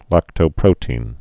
(lăktō-prōtēn, -tē-ən)